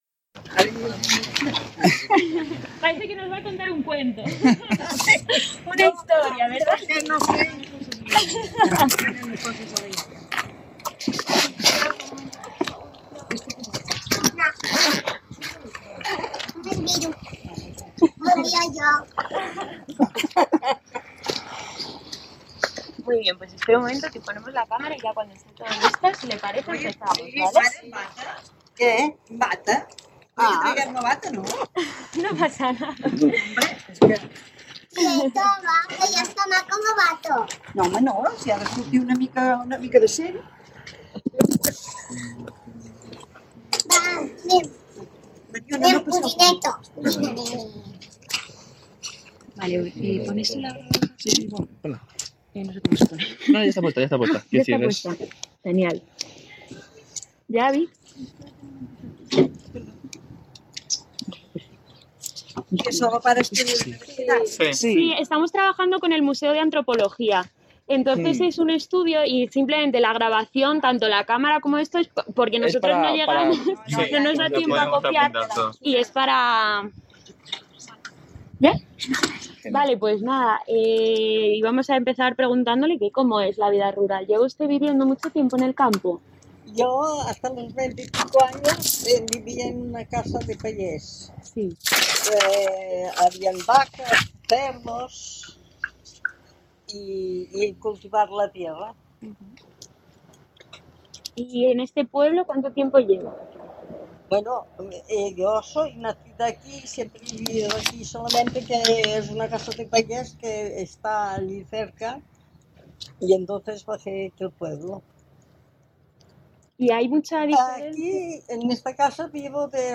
Espinelves
mujer